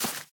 Minecraft Version Minecraft Version snapshot Latest Release | Latest Snapshot snapshot / assets / minecraft / sounds / block / cave_vines / break1.ogg Compare With Compare With Latest Release | Latest Snapshot